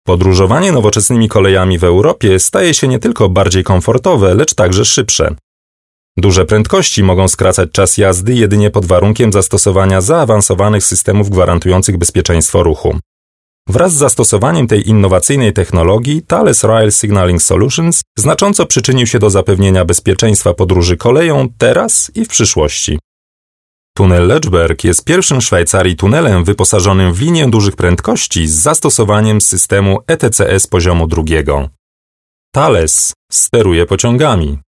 Sprecher polnisch.
Kein Dialekt
Sprechprobe: Werbung (Muttersprache):